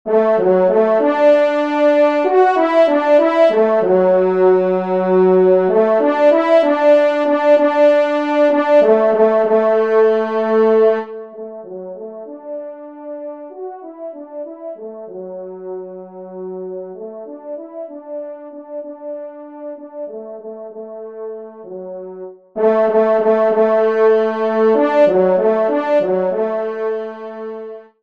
Genre : Divertissement pour Trompes ou Cors
Pupitre 2° Cor